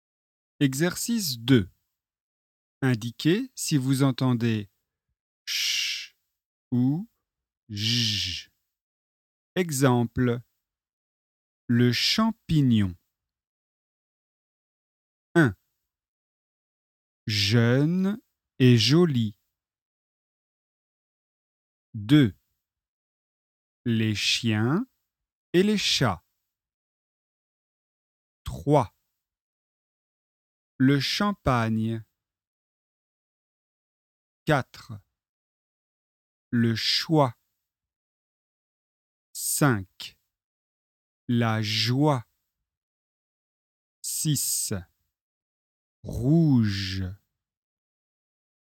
Leçon de phonétique et exercice de prononciation